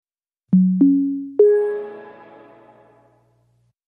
windows-11-start-sound-a.mp3